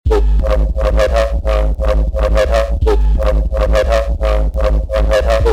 drum & bass samples
Bass Full 3 G#-A
Bass-Full-3-G-A.mp3